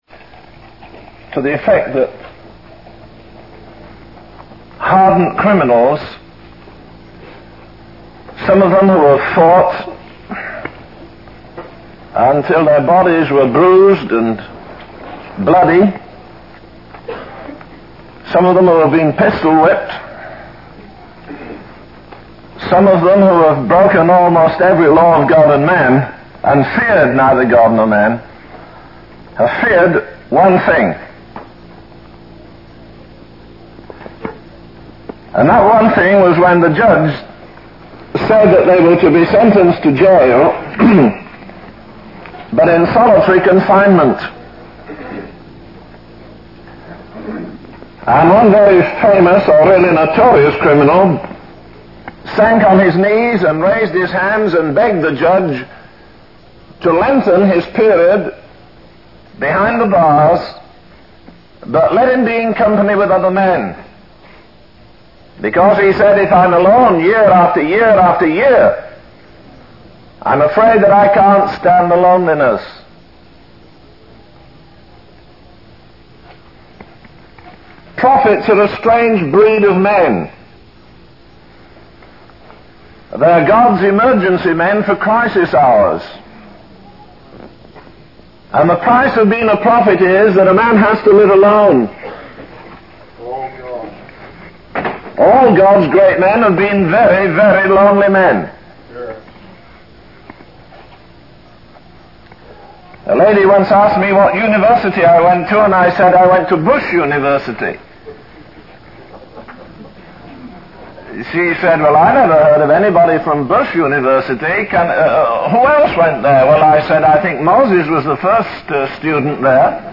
In this sermon, the preacher emphasizes the importance of having a genuine relationship with God rather than seeking material success and prestige. He highlights the role of John the Baptist in introducing Jesus and the significance of Jesus' baptism.
The sermon concludes with a personal testimony shared by the preacher at a missionary rally.